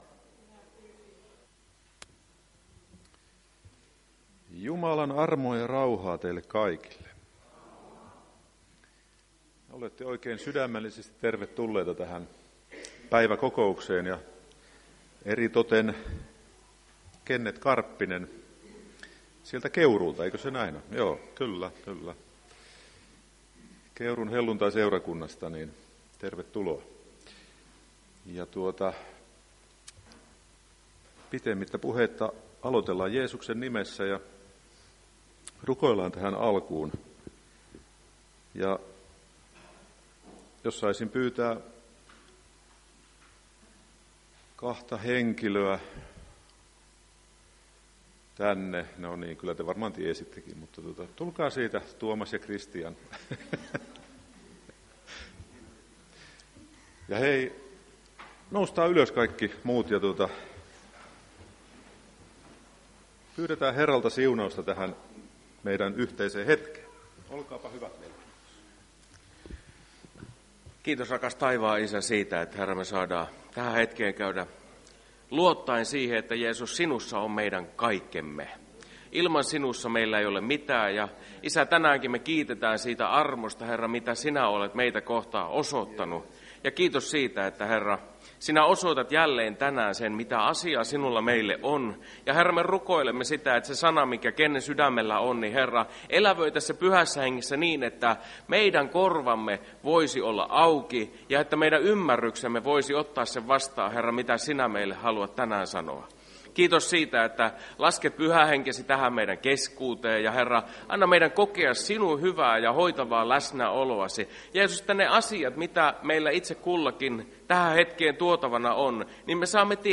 Päiväkokous 9.3.2025